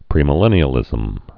(prēmĭ-lĕnē-ə-lĭzəm)